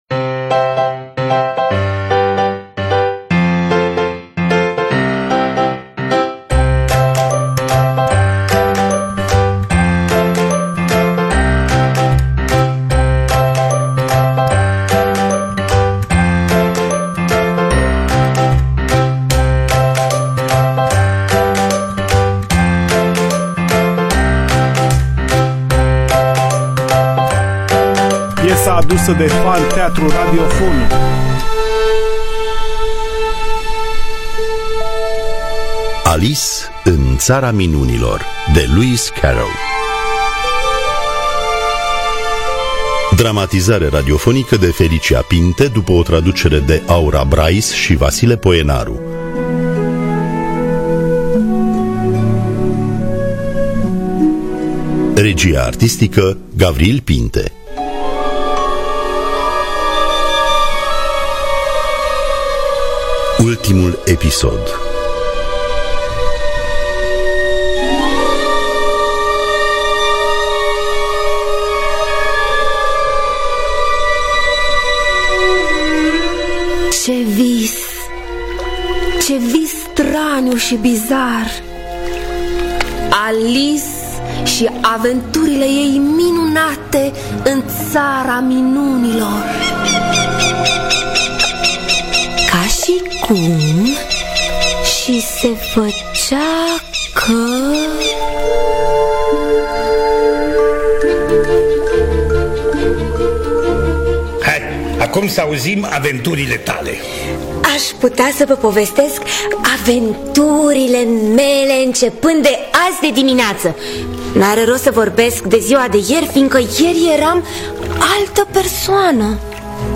Dramatizarea radiofonică
Înregistrare din anul 2019 (octombrie; premiera pe 10 noiembrie).